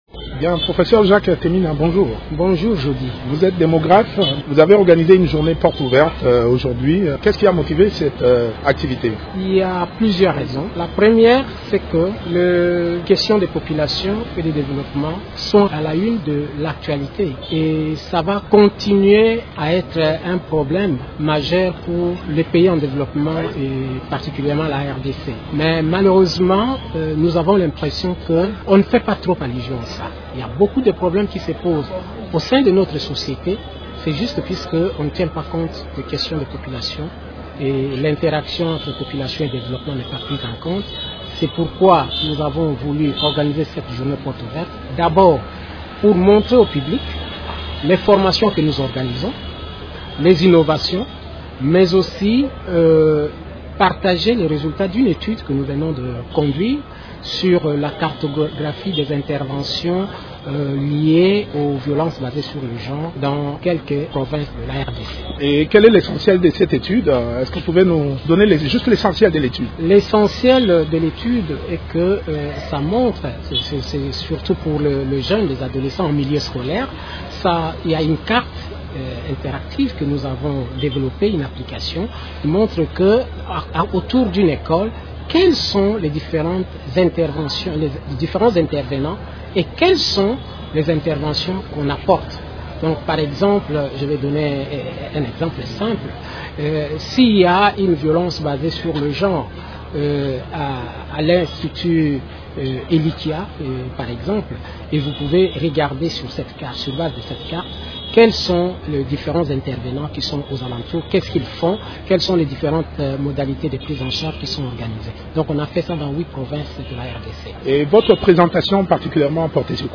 parle de cette activité au micro de